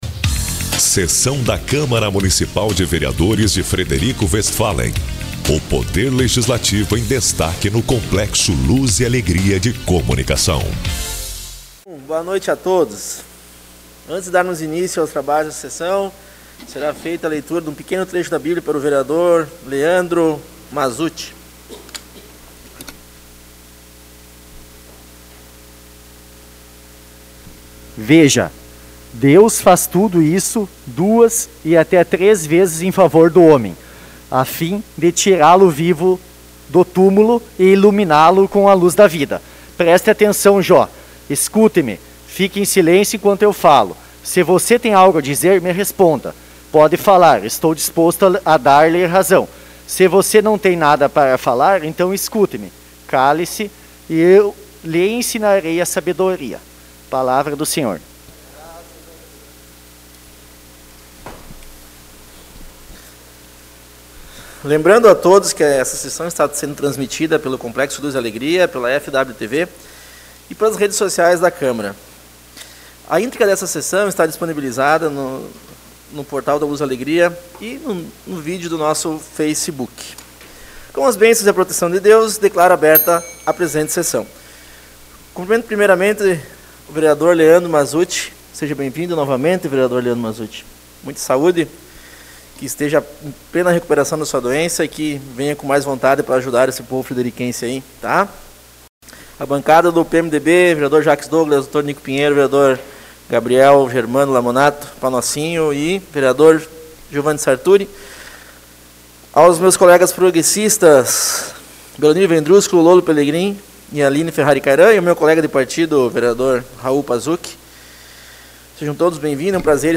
Sessão ordinária 06 de abril